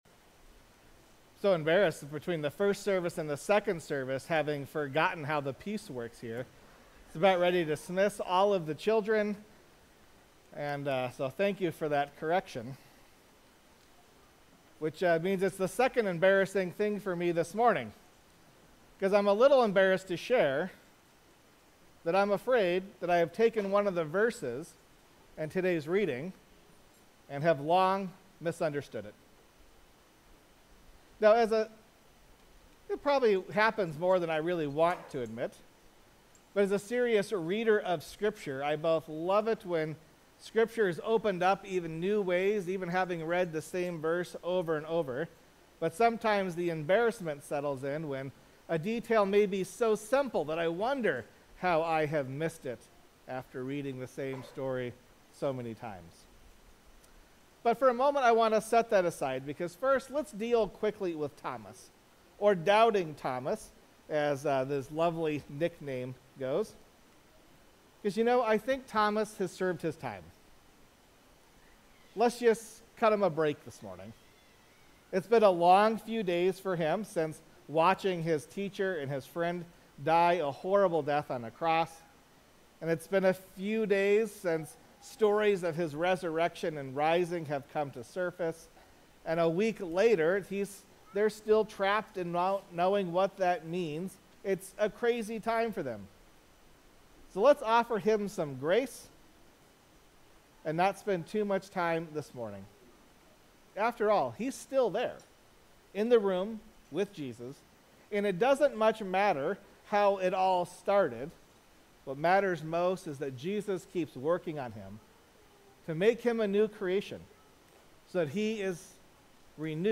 4.27.25-Sermon.mp3